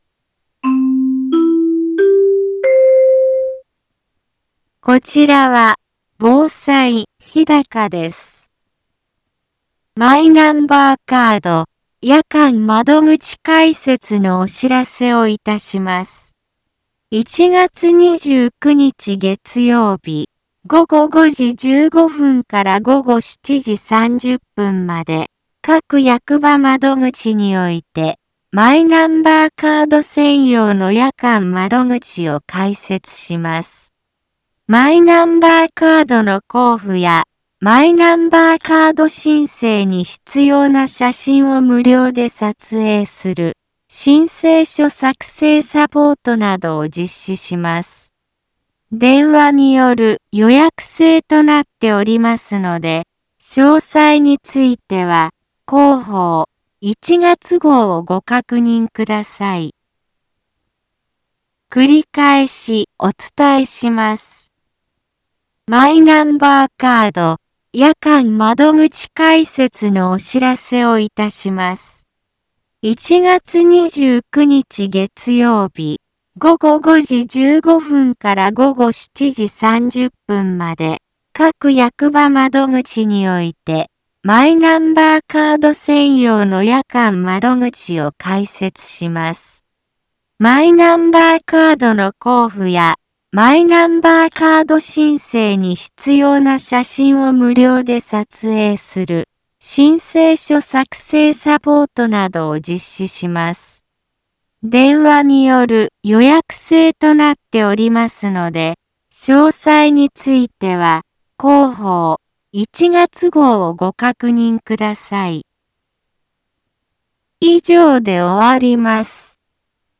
Back Home 一般放送情報 音声放送 再生 一般放送情報 登録日時：2024-01-22 15:04:31 タイトル：マイナンバーカード夜間窓口開設のお知らせ インフォメーション： マイナンバーカード夜間窓口開設のお知らせをいたします。 1月29日月曜日、午後5時15分から午後7時30分まで、各役場窓口において、マイナンバーカード専用の夜間窓口を開設します。